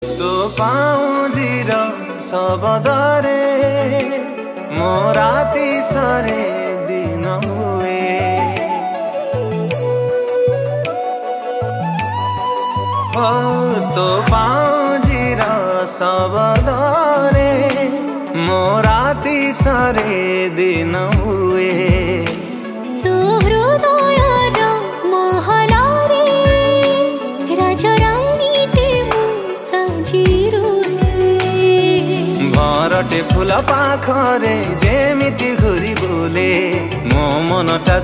Odia Ringtones
dance song